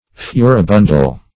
Search Result for " furibundal" : The Collaborative International Dictionary of English v.0.48: Furibundal \Fu`ri*bun"dal\, a. [L. furibundus, fr. furere to rage.]